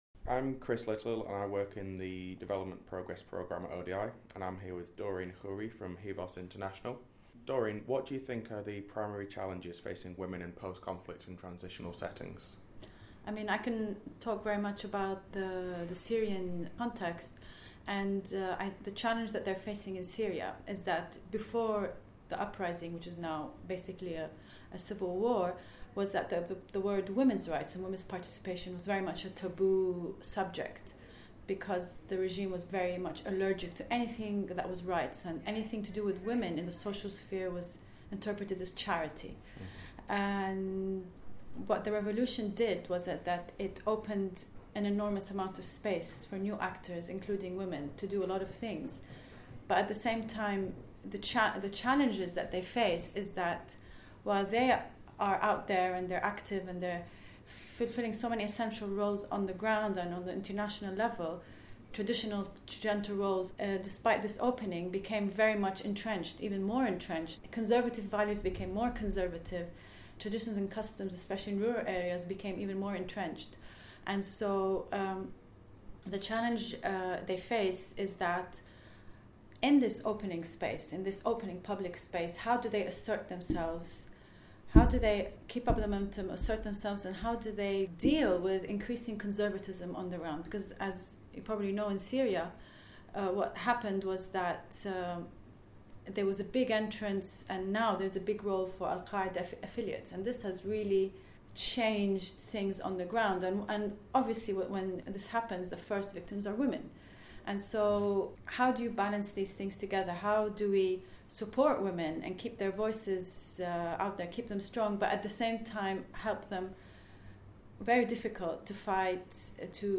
At ODI's roundtable